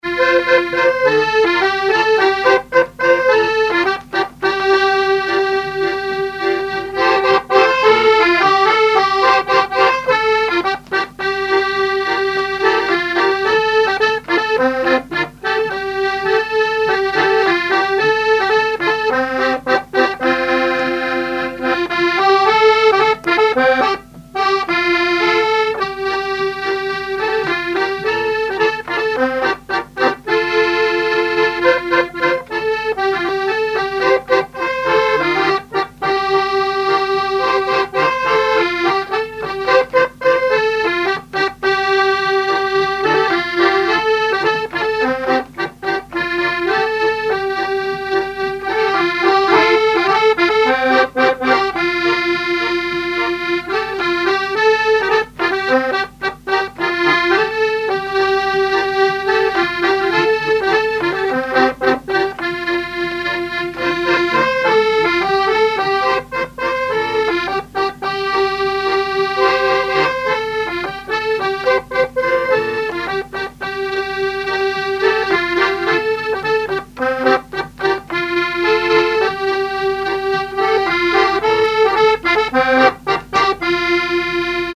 Mémoires et Patrimoines vivants - RaddO est une base de données d'archives iconographiques et sonores.
Fonction d'après l'informateur gestuel : à marcher
Genre énumérative
Catégorie Pièce musicale inédite